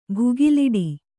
♪ bhugiliḍi